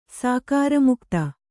♪ sākāra mukta